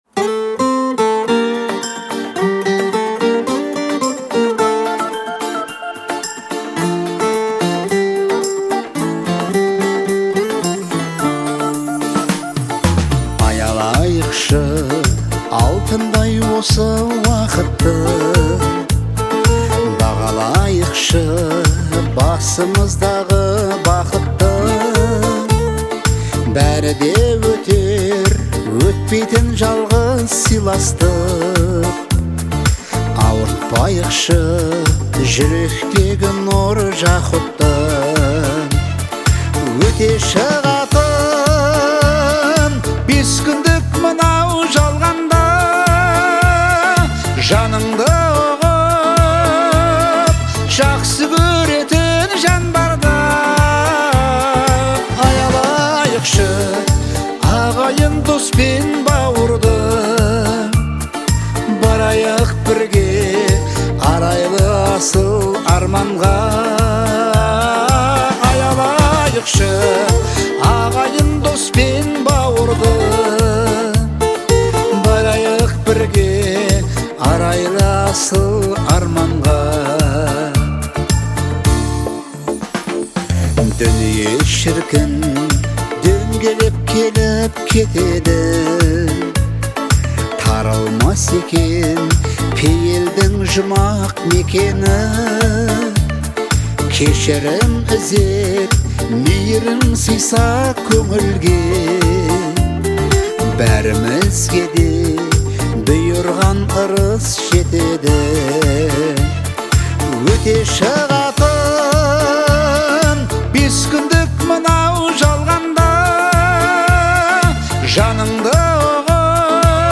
сочетающий элементы поп и фолка.